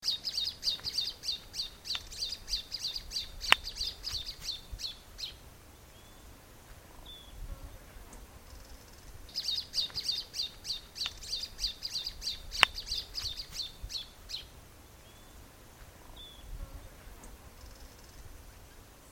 Tio-tio-pequeno (Phacellodomus sibilatrix)
Nome em Inglês: Little Thornbird
Fase da vida: Adulto
Localidade ou área protegida: Tres Marías
Condição: Selvagem
Certeza: Observado, Gravado Vocal